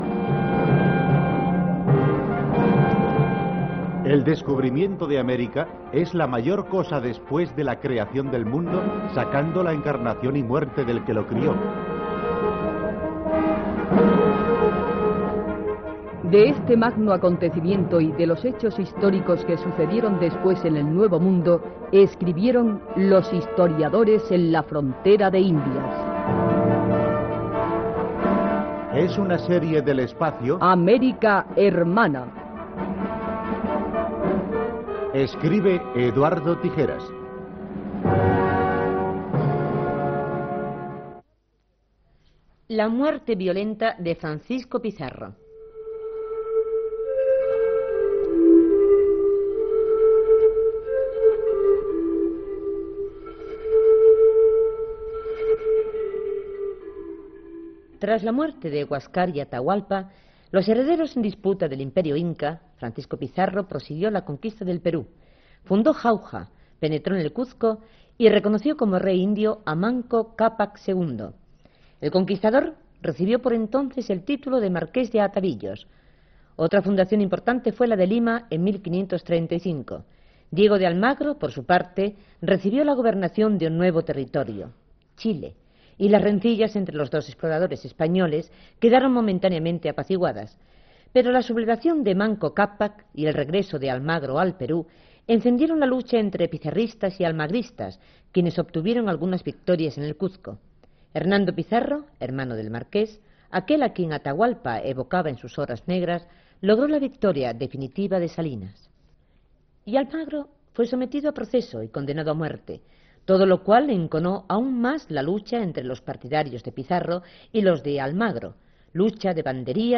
Presentació i primers minuts de l'espai dedicat a l'assassinat del conqueridor Francisco de Pizarro, amb recreacions dramàtiques.
Divulgació